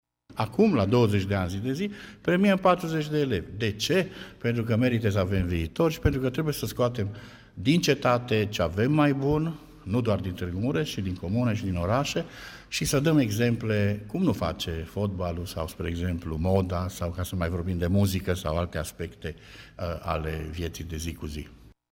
Festivitatea de premiere a avut loc la prânz, în Sala mică a Palatului Culturii din Târgu Mureș.